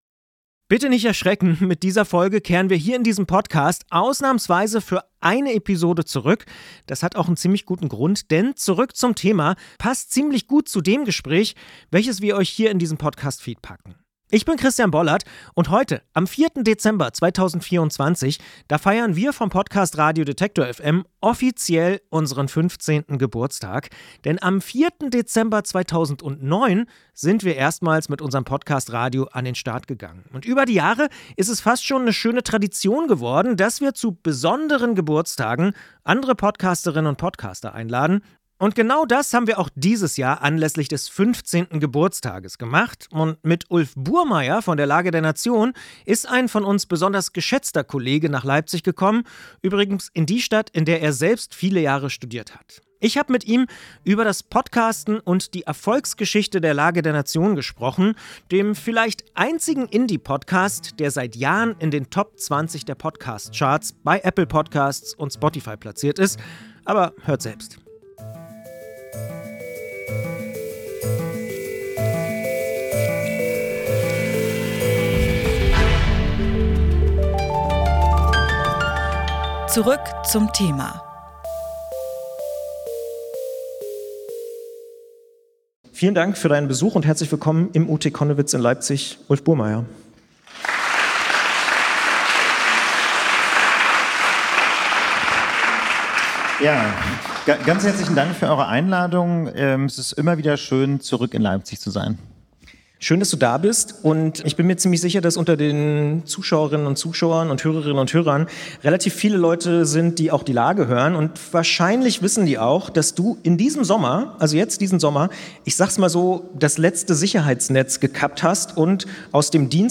vor Live-Publikum getroffen